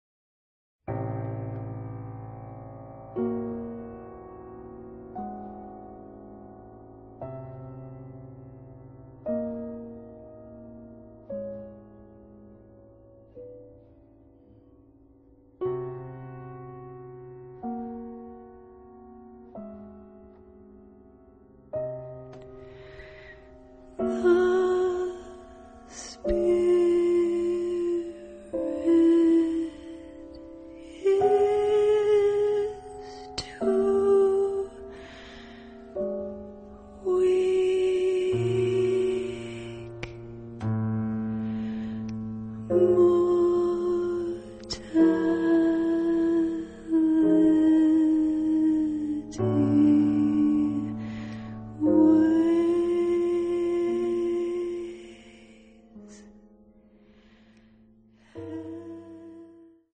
piano, voice
understated avant-garde.